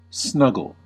Ääntäminen
IPA: /ˈanˌʃmiːɡən/ IPA: [ˈʔanˌʃmiːɡŋ̍]